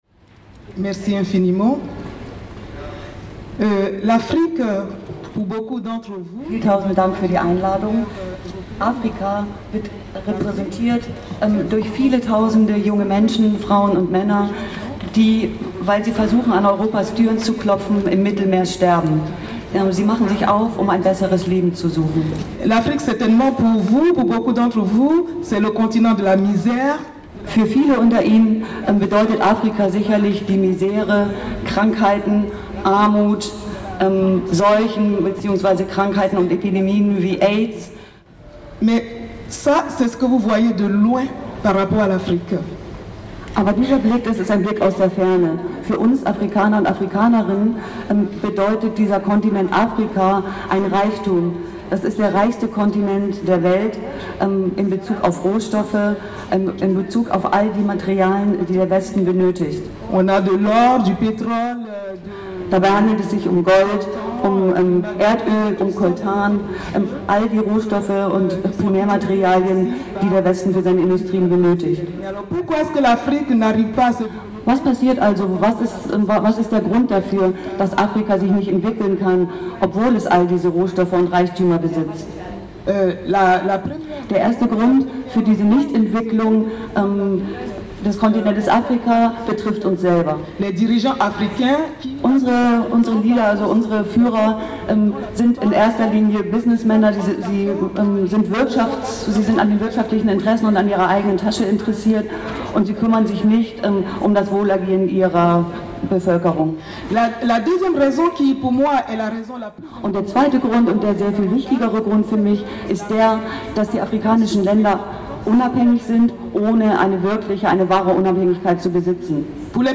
Und auch die ehemalige Sozialministerin der Elfenbeinküste Clotilde Ohoucchi hat auf dem Gipfel für globale Solidarität eine Rede gehalten und deutlich gemacht, was sie an der aktuellen politischen Situation und am G20-Gipfel stört.